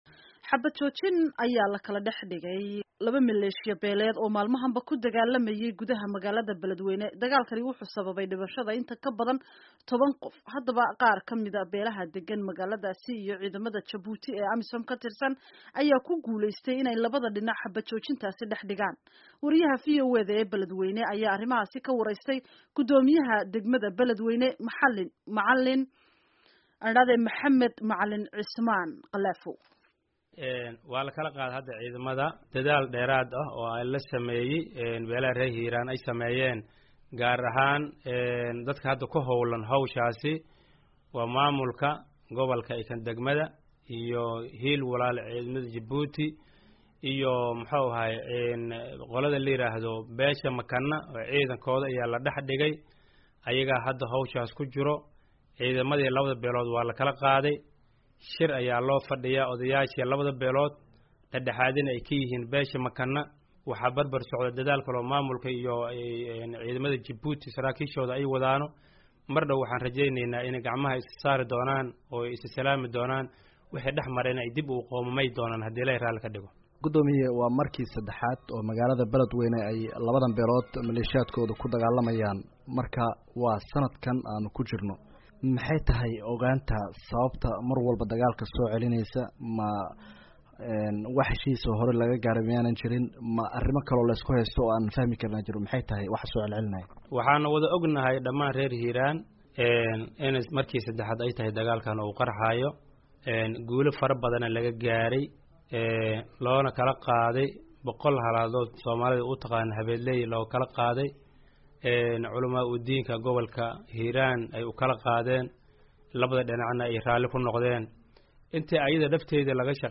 Wareysi Xabbad-joojinta Dagaalka Beledwyne